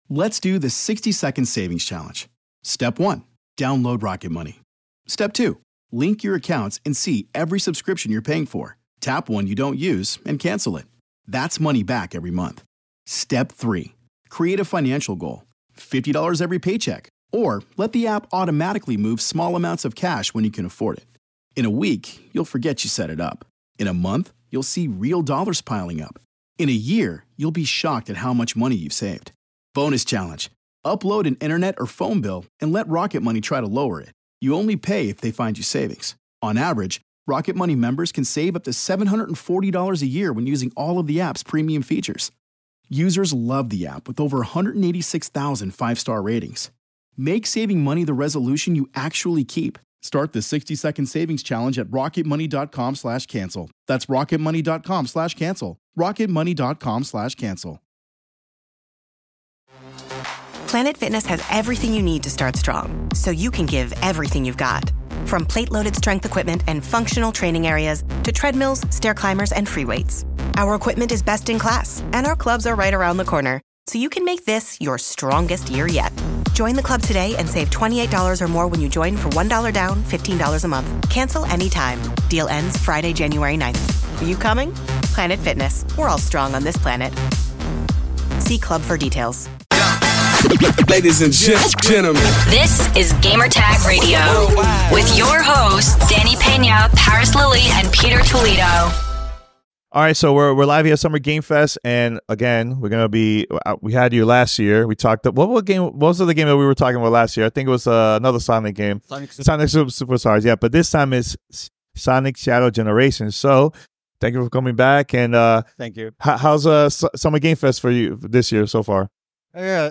Sonic X Shadow Generations: Takashi Iizuka Interview